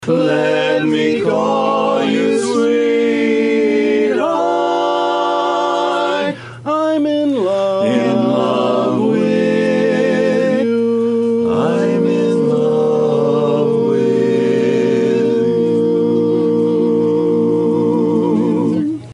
tenor
lead
bass
baritone
The Little Apple Barbershop Chorus in Manhattan is once again providing a unique gift idea, through the power of music, as heard on the KMAN Morning Show Tuesday.
That is Charades, one of four Little Apple Chorus barbershop quartets this week delivering singing valentines.